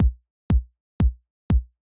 ITA Beat - Kicks.wav